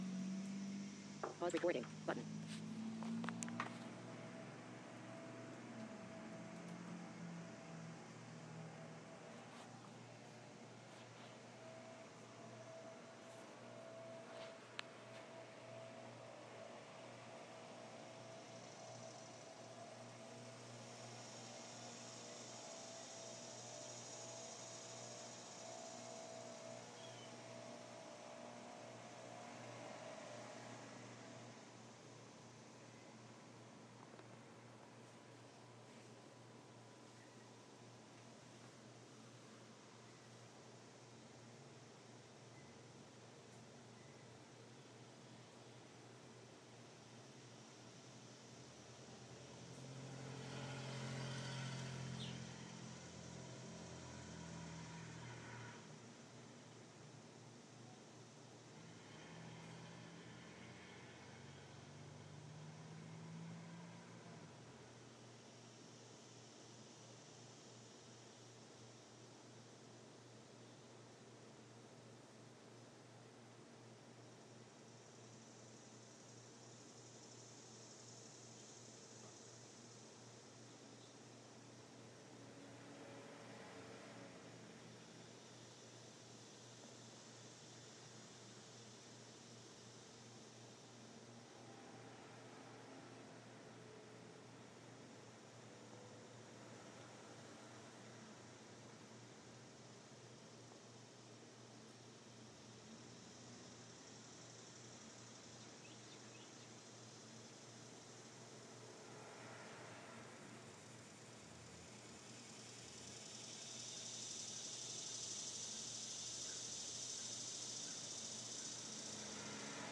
Cicadas and birds